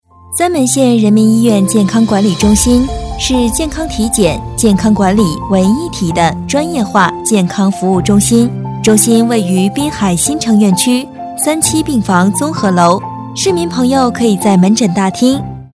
女277-专题-三门县医院
女277角色广告专题 v277
女277-专题-三门县医院.mp3